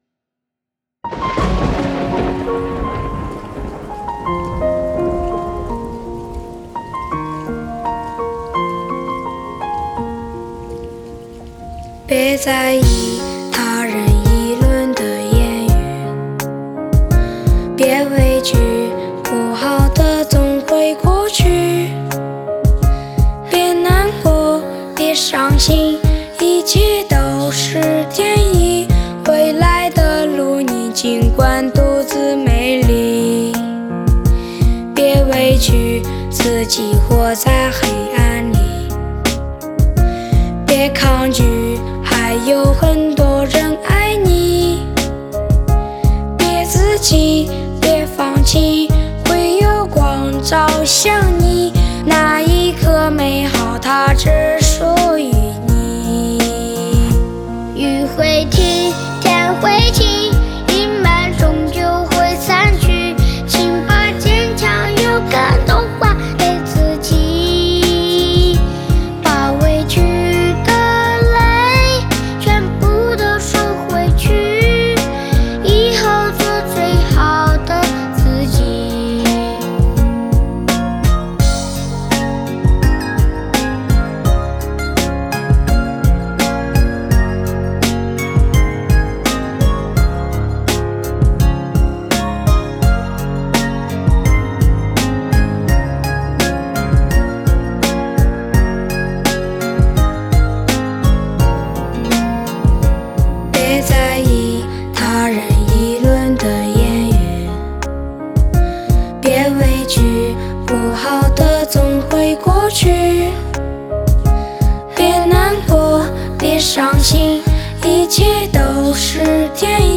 好像有个小孩子的声音，声音很甜的